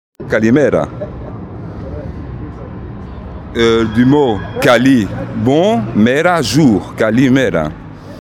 explication Kalimera. Du mot kali - bon et mera - jour.